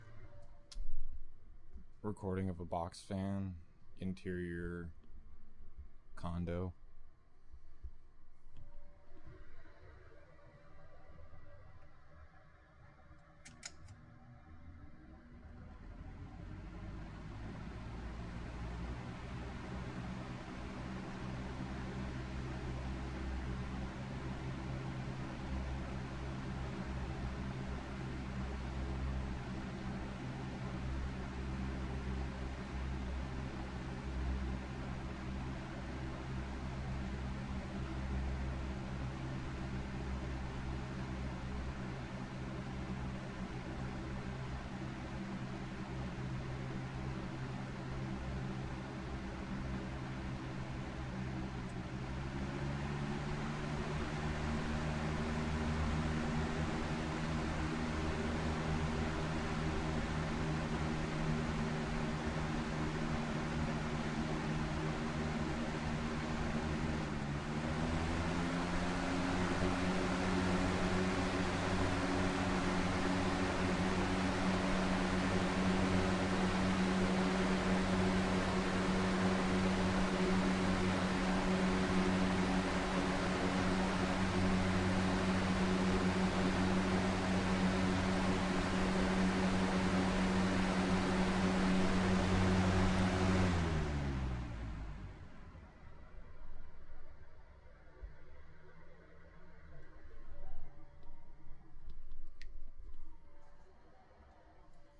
描述：在箱式风扇前测试我的Rode blimp挡风玻璃，以确保它能够处理点空白风源而不会吹出录音。
Tag: 电机 风机 氛围